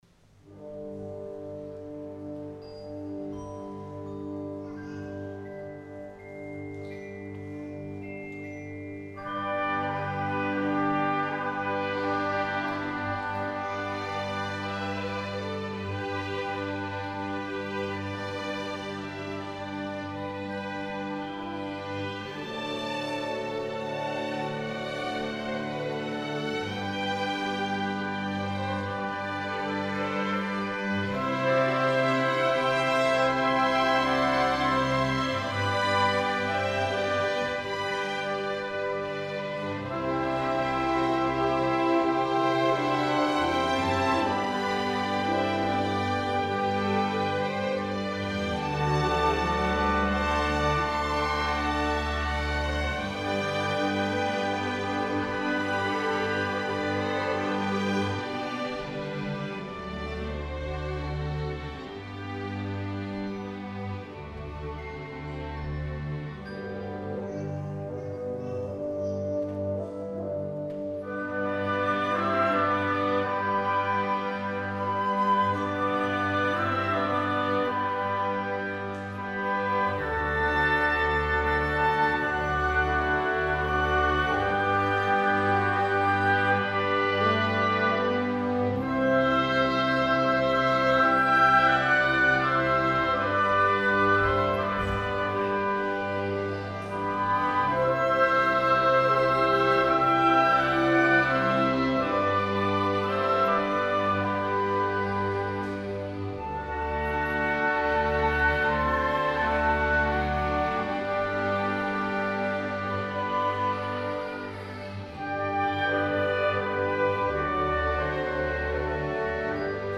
“Αγια Νύχτα” για Ορχήστρα (live)